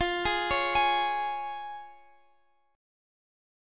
Listen to Fm#5 strummed